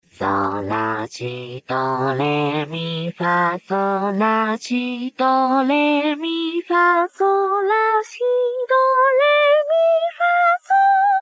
性別：少年のロボット
幸JPVCV_甘やか（sweet）                    DL
収録音階：D#4  G4